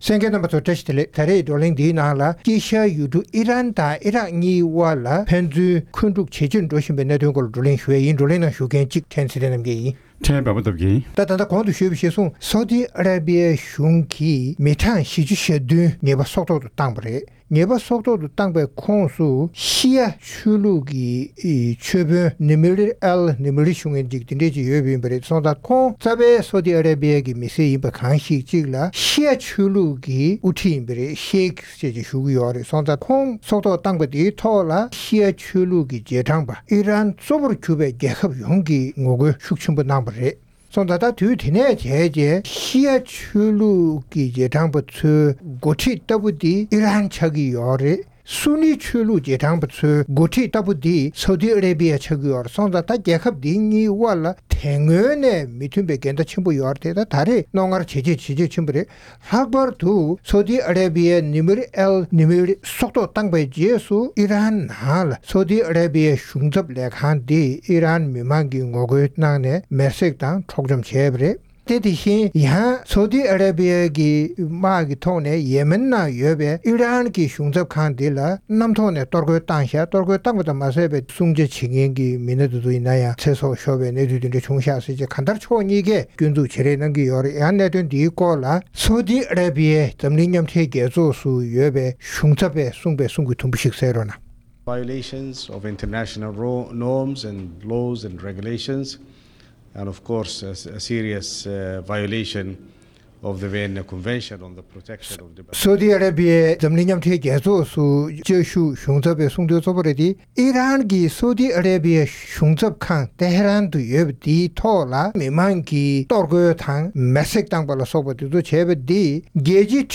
༄༅༎ཐེངས་འདིའི་རྩོམ་སྒྲིག་པའི་གླེང་སྟེགས་ཞེས་པའི་ལེ་ཚན་ནང་།དཀྱིལ་ཤར་ཡུལ་གྲུའི་ནང་དམག་འཁྲུགས་ཀྱི་དཀའ་སྡུག་ཡོད་མུས་ཐོག་Saudi Arabia དང་། Iran གཉིས་དབར་འཁོན་འཁྲུགས་ཇེ་ཆེར་འགྲོ་བཞིན་པའི་སྐོར་རྩོམ་སྒྲིག་འགན་འཛིན་རྣམ་པས་བགྲོ་གླེང་གནང་བ་ཞིག་གསན་རོགས་གནང་༎